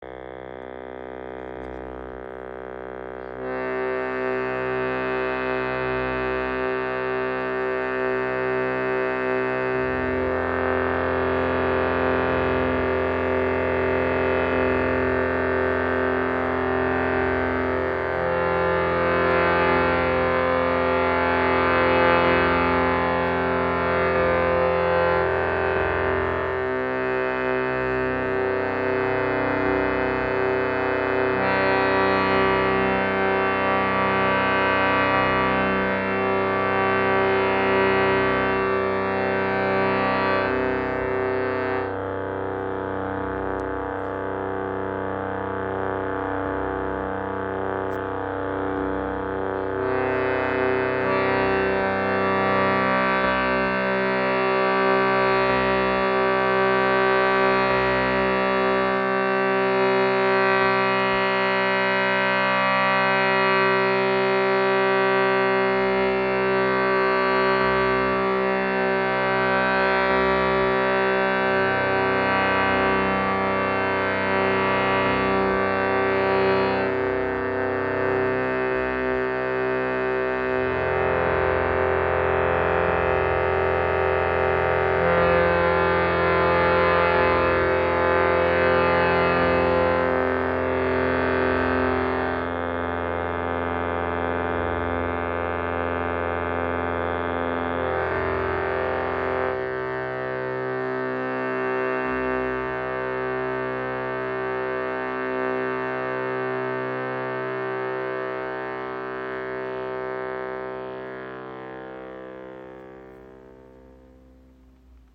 RADHA Shruti Box | 2. Oktaven | Tonumfang C2–B3 | in 440 oder 432 Hz
• Icon Besonders gleichmässiger Klangfluss dank doppeltem Blasebalg.
Die tiefere Oktave (C2–B3) liefert warme, erdige Klänge – ideal für Meditation und tiefe Stimmen. Die höhere Oktave (C3–B3) dagegen bringt helle, leichte Töne hervor, die besonders gut zu helleren Stimmen oder Obertongesang passen.